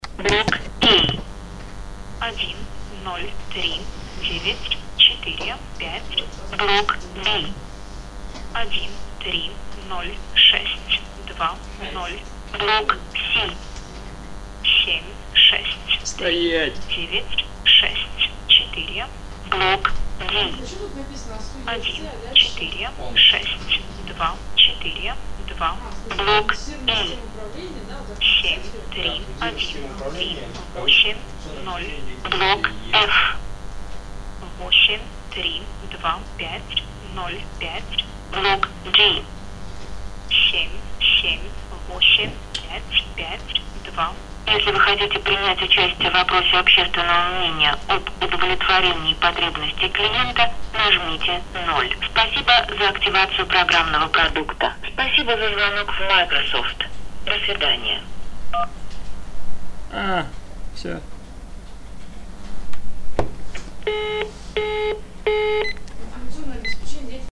Но эт фигня - в ответ мне автоматический женский голосок принялся надиктовывать ответные циферки.
Даж запись монолога автоответчика успел сделать :-) Особенно порадовало произнесение буквы "Шесть".